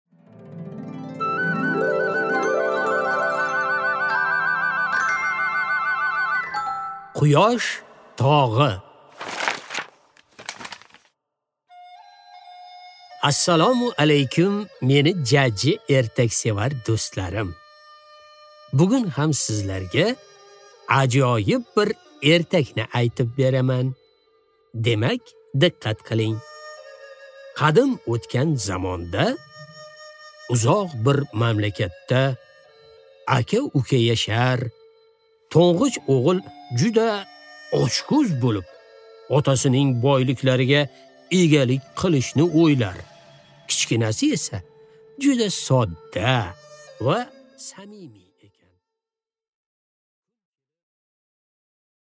Аудиокнига Quyosh tog'i